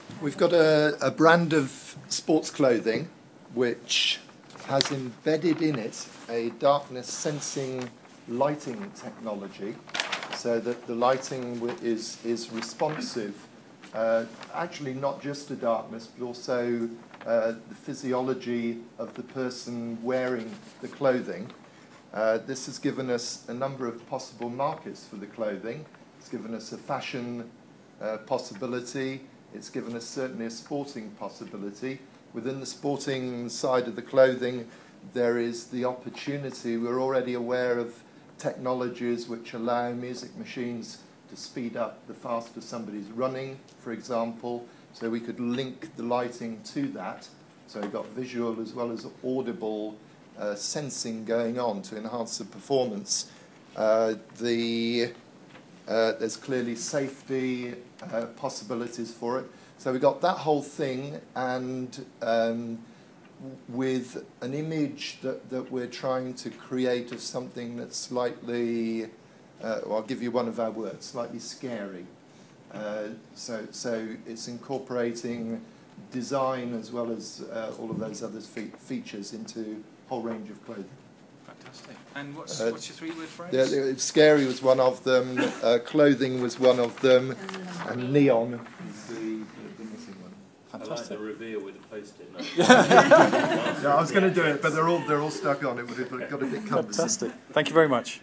ICE House discussion